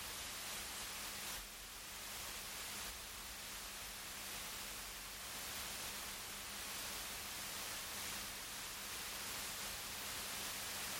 The low frequencies have some high frequencies, with the one at 200 Hz standing out.
I have recorded the signals shown above, but please keep in mind that I’ve enabled Automatic Gain Control (AGC) to do so to make it easier for you to reproduce them.
10% Fan Speed